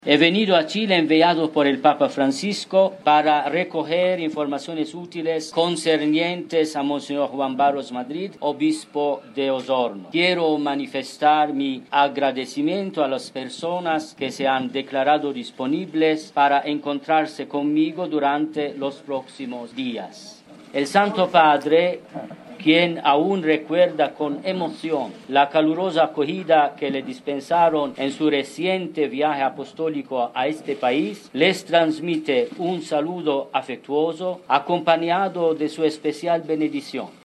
En breves declaraciones, el arzobispo de Malta expresó mediante la lectura de un texto que «he venido a Chile enviado por el papa Francisco para recoger informaciones útiles concernientes a monseñor Juan Barros Madrid», asimismo, agradeció a las personas que están reuniéndose con él en este proceso.